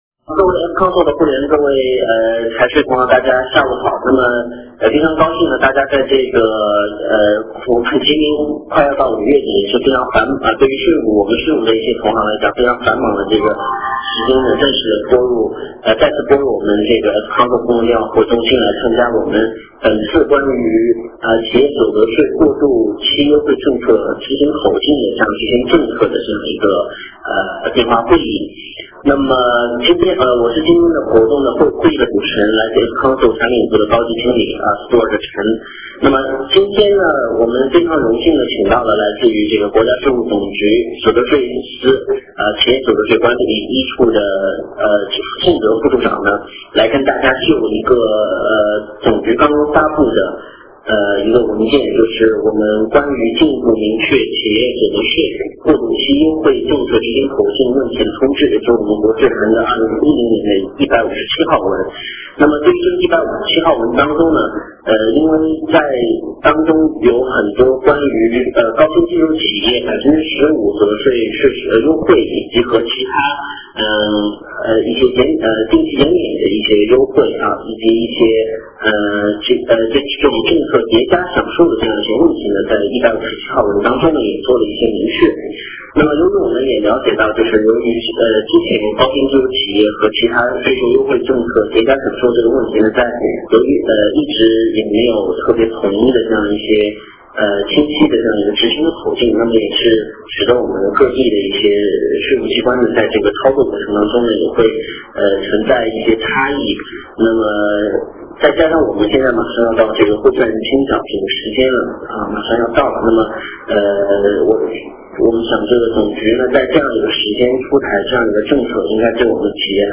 电话会议
国家税务总局所得税司企业所得税一处官员